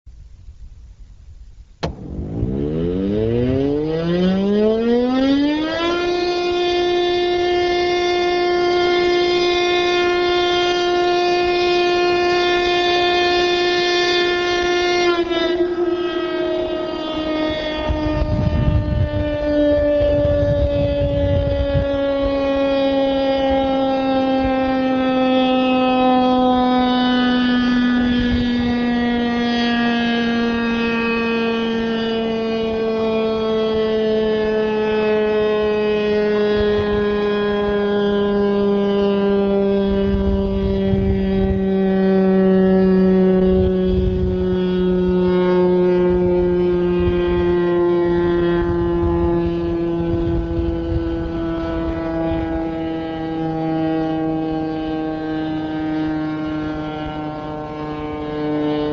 昼のサイレン
毎日、昼に市内中心部にある神武山山頂から鳴らされるサイレンです。
siren.mp3